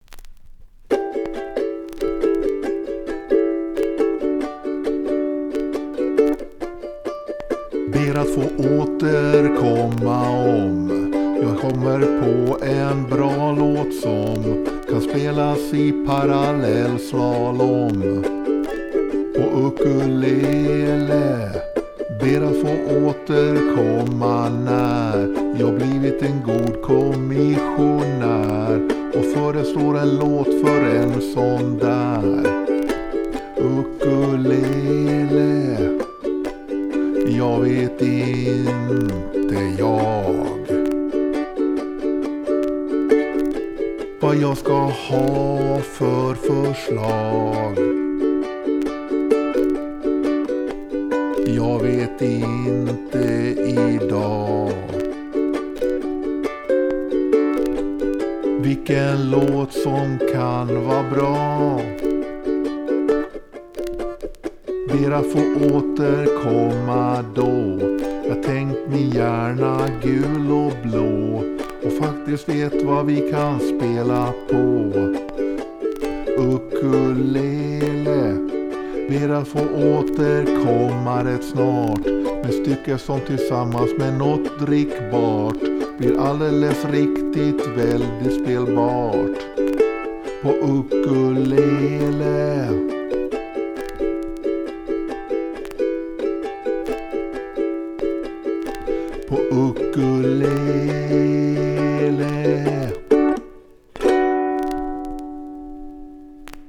I alla fall s� digitaliserade jag sp�ret fr�n min bootleg.
Jag tycker att det verkar som att ackorden �r D G A7, ent takt av varje i refr�ngen och G D A7 D, tv� takter var i sticket. Och s� ett intro och ett outro, men det r�knar ni l�tt ut.